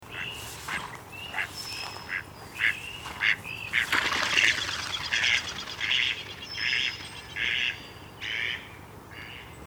PATO MEXICANO (Anas Diazi).
pato-mexicano.mp3